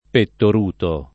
DOP: Dizionario di Ortografia e Pronunzia della lingua italiana
pettoruto